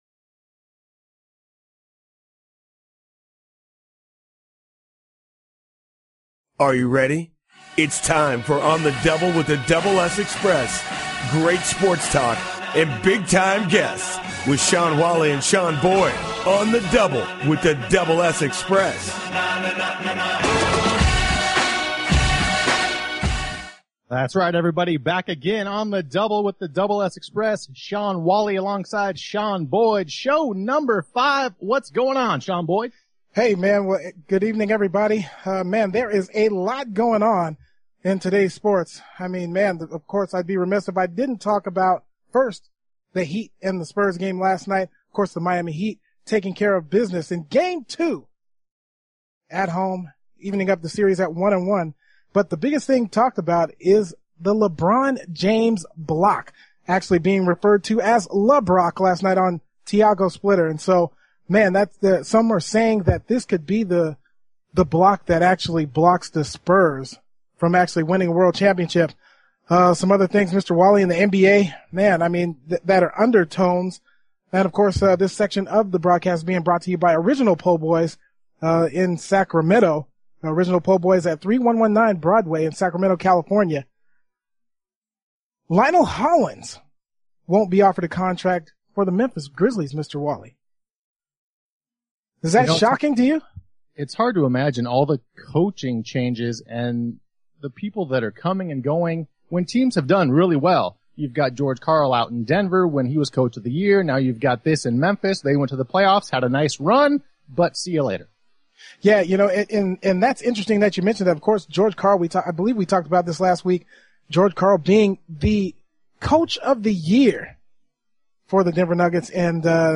Talk Show Episode
sports talk show